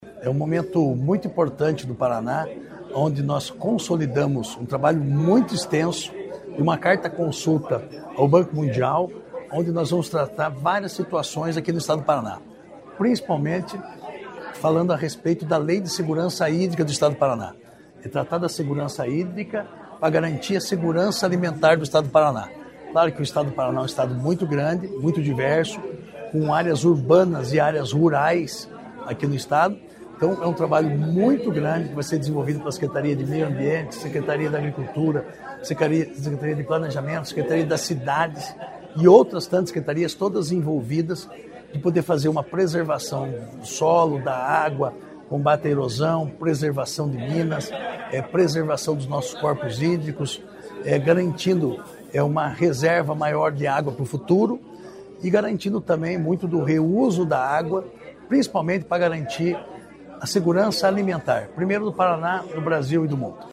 Sonora do secretário Estadual da Agricultura e Abastecimento, Marcio Nunes, sobre as ações do Programa de Segurança Hídrica